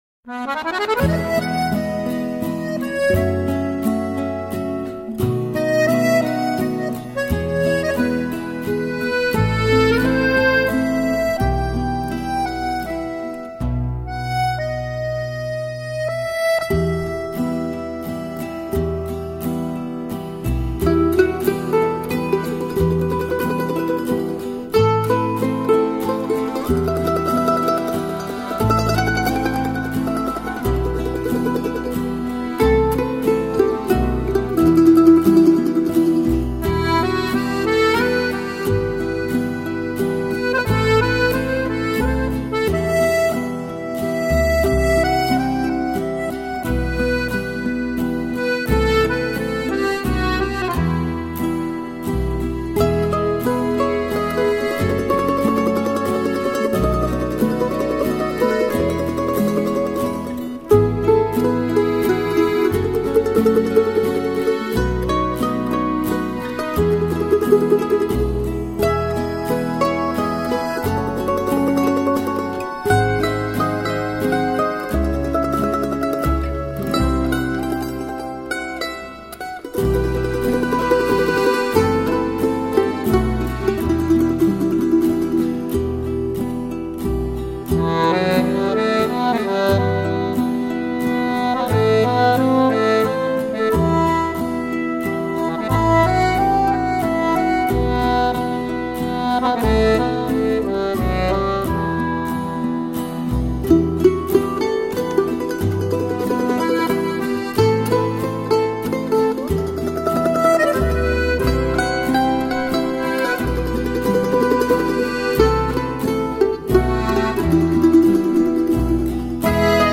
曲风优美，伴奏轻快，录音效果也相当出 色。
唱片所采用的是传统欧洲色 彩乐器，如小提琴、结他、手风琴和竖琴等，营造出浪漫的色彩浓烈的气氛。
轻快热情的节奏，几件乐器不停 的轮番上场独白，令音响画面美丽流畅而富立体效果。